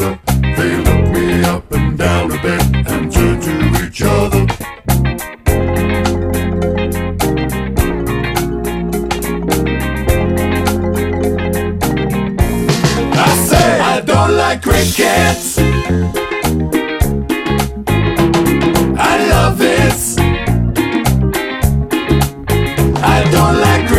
Two Semitones Down Pop (1970s) 5:00 Buy £1.50